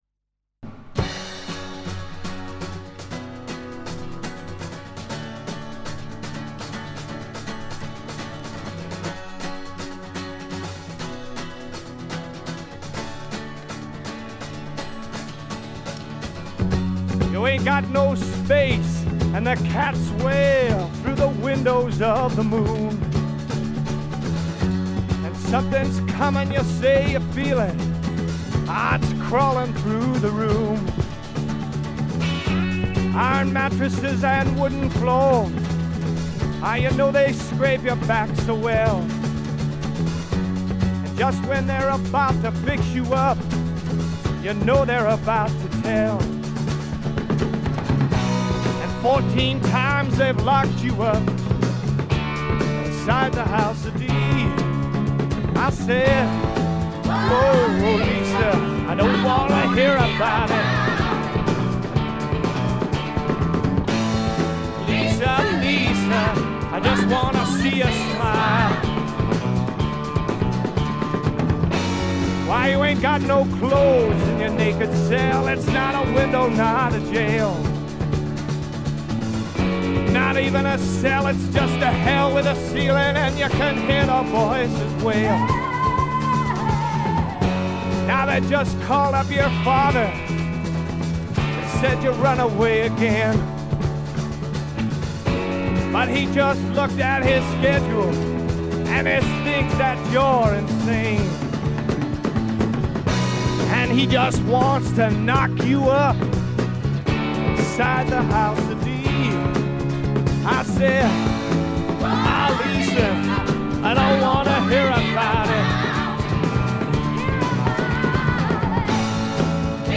静音部で軽微なバックグラウンドノイズが少し。
乾ききった硬質感で統一された見事なスワンプロック！
試聴曲は現品からの取り込み音源です。